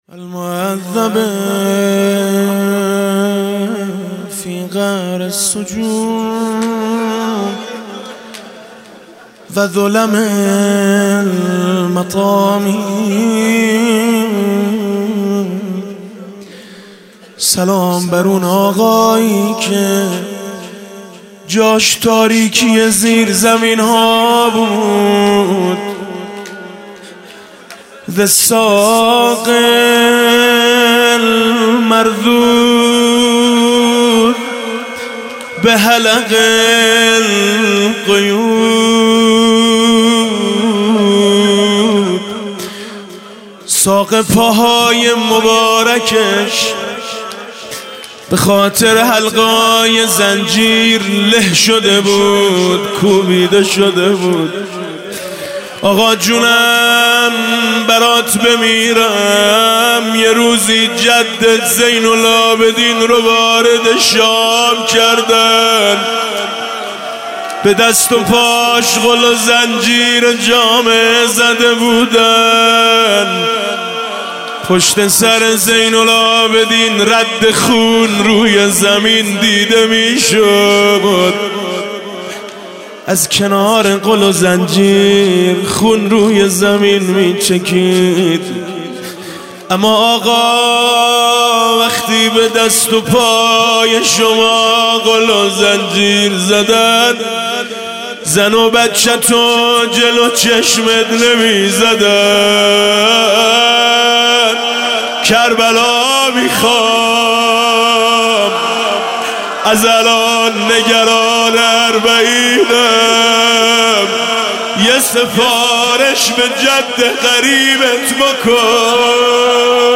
روضه: اَلسّلَام عَلی الْمُعَذَّبِ فِي قَعْرِ السُّجُونِ